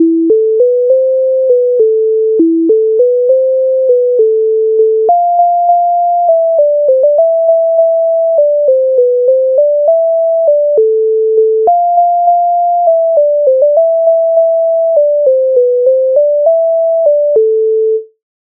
MIDI файл завантажено в тональності a-moll
Ой чий то кінь стоїть Українська народна пісня